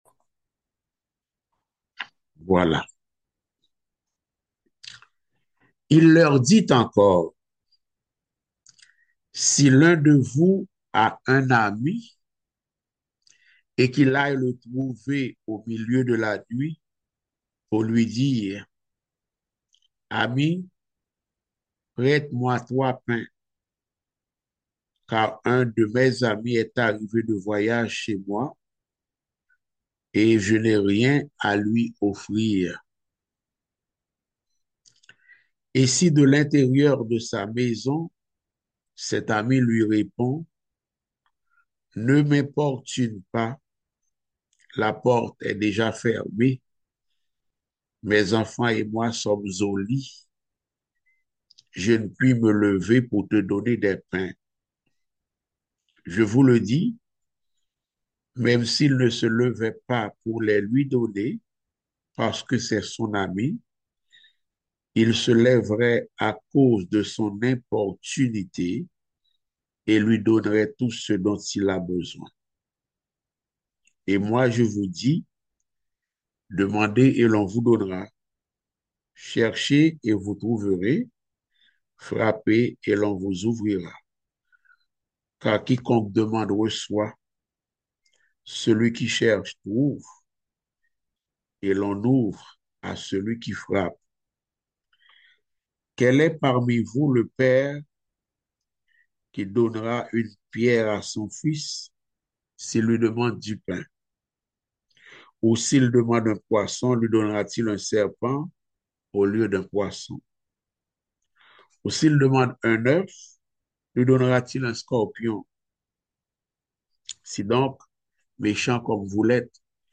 Luc 18.1-8 Type De Service: Études Bibliques « Les leçons spirituelles du livre des Rois.